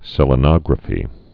(sĕlə-nŏgrə-fē)